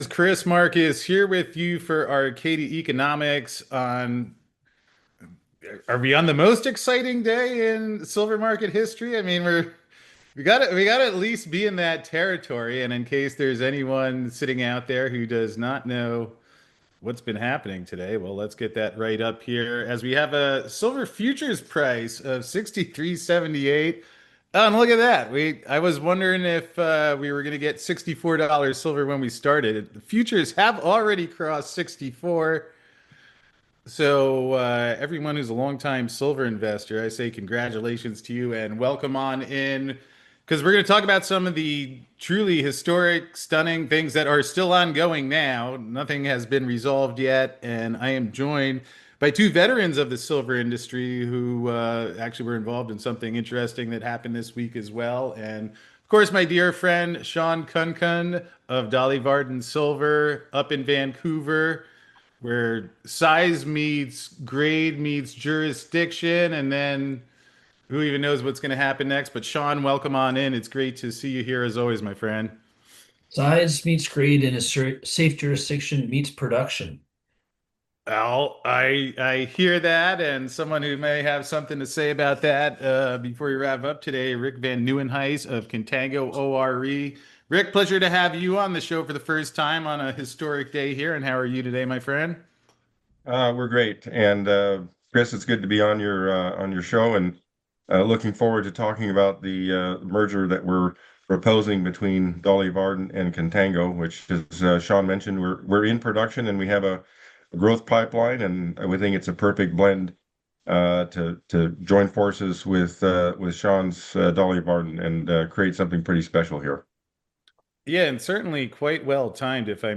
Beschreibung vor 1 Monat Silver Futures Break Above $63 As Lease Rates Surge Again So far on Thursday morning the silver rally doesn't seem to have any interest in slowing down, as the price is surging again, while the lease rates have spiked. It's another wild day in the precious metals markets, and to find out more about what's happening up to the minute, join us for this live call at 11 a.m. Eastern!